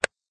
click_hard_wood.ogg